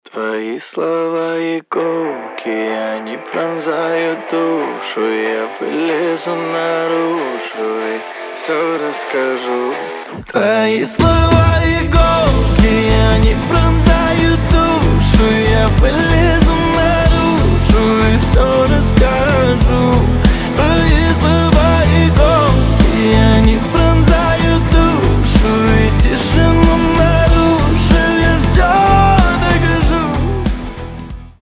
поп
красивый мужской голос